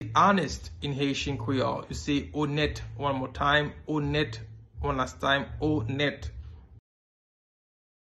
Pronunciation:
14.How-to-say-Honest-in-Haitian-Creole-–-Onet-pronunciation.mp3